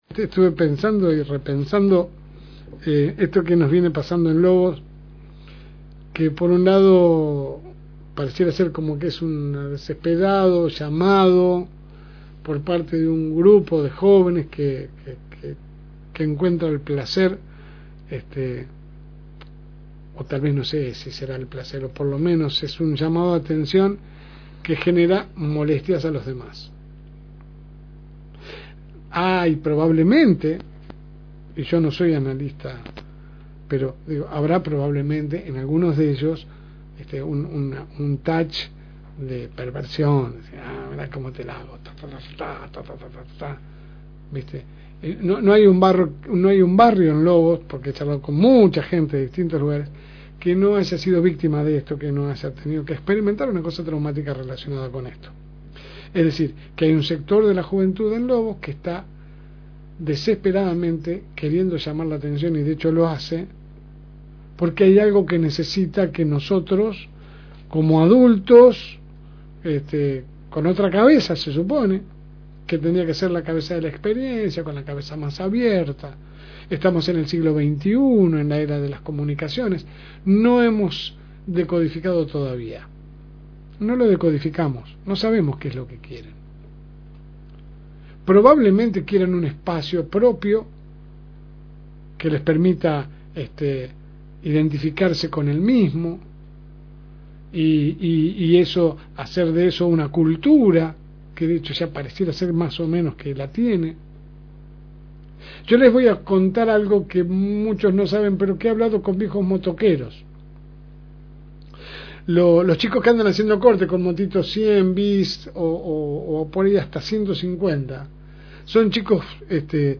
AUDIO – Editorial LSM
Compartimos con ustedes la editorial de la semana